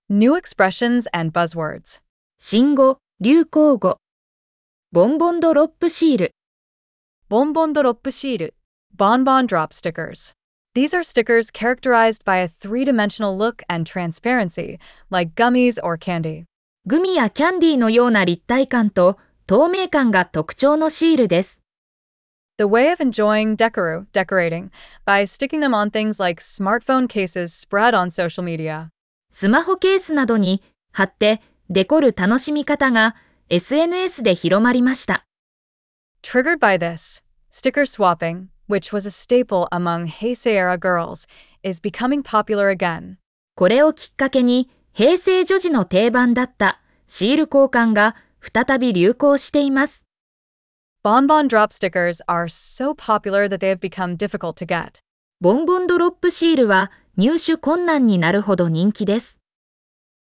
🗣 pronounced: Bonbon doroppu shi-ru